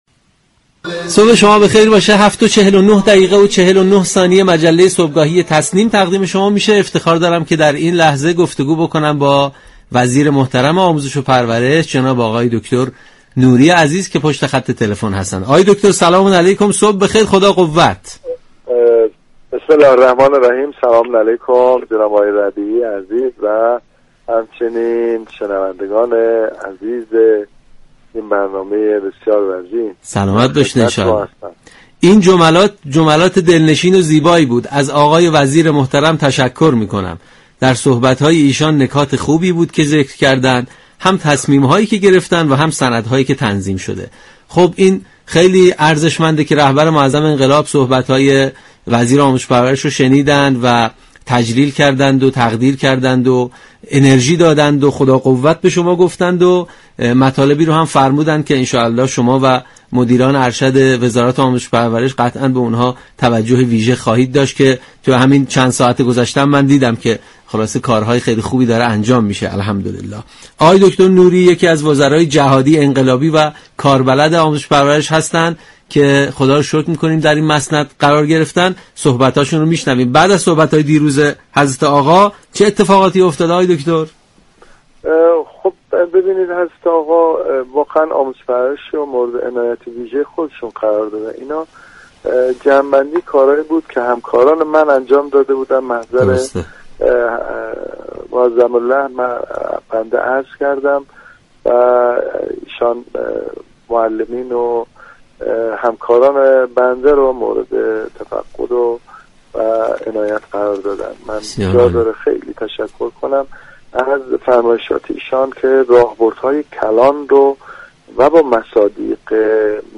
وزیر آموزش در گفتگو با رادیو قرآن عنوان كرد؛ قدردانی مقام معظم رهبری از جامعه فرهنگیان كشور سرمایه كلانی است